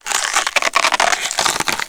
ALIEN_Insect_01_mono.wav